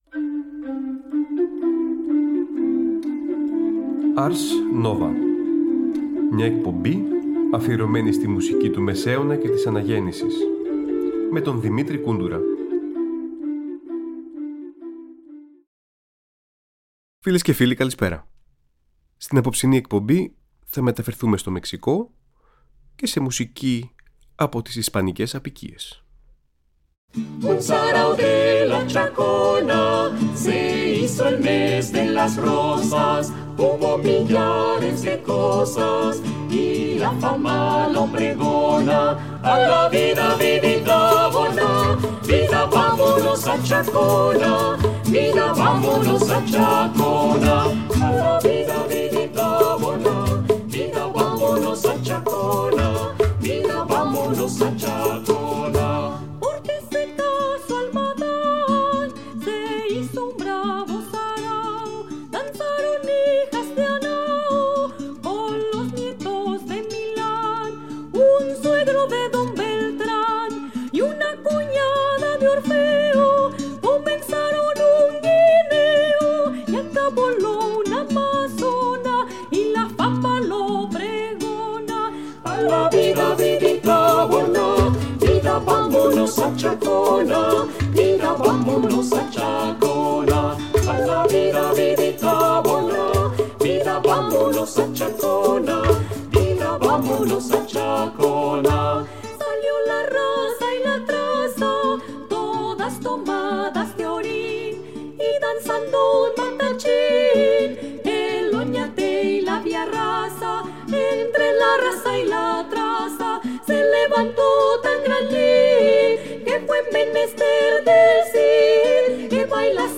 Ισπανοί συνθέτες αναζητούν μια καλύτερη τύχη στις αποικίες ενώ ταυτόχρονα δημιουργείται και ένα τοπικό μουσικό ιδίωμα. Ένα αφιέρωμα στην Ισπανόφωνη Αναγέννηση με έργα των Gaspar Fernandez, Hernando Francisco κ.α.